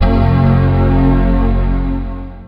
OCEFIAudio_VoiceOver_Boot.wav